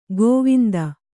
♪ gōvinda